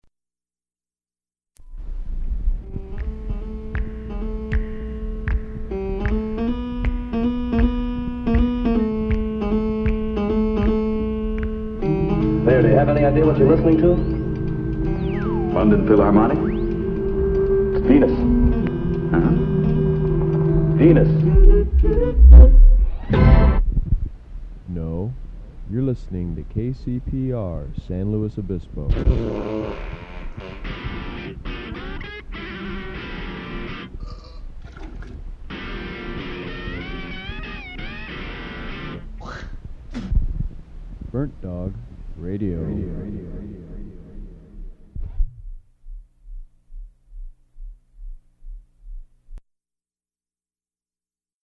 Legal [station] ID: Burnt Dog Radio
Form of original Audiocassette